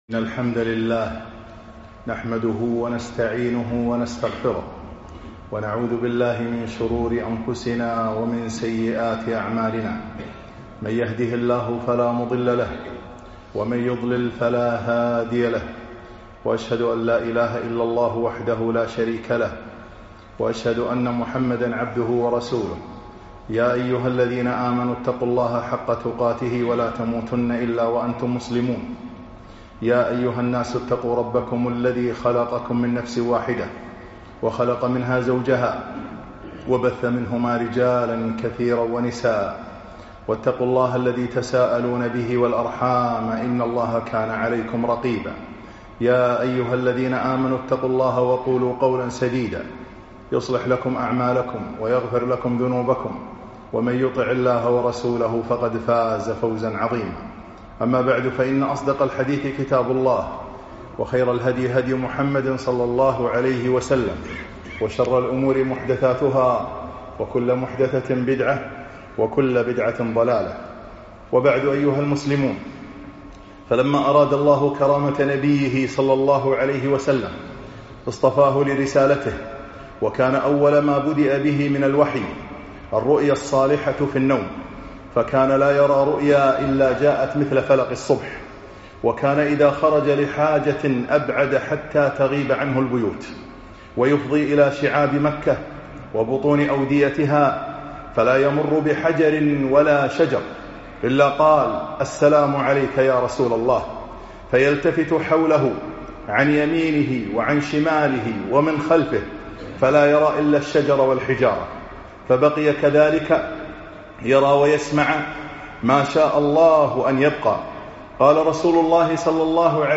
خطب السيرة النبوية 4